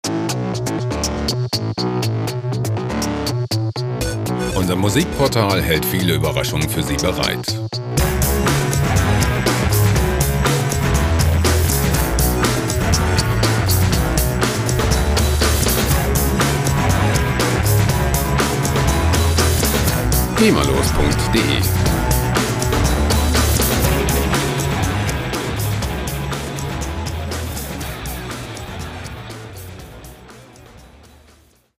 • Electropunk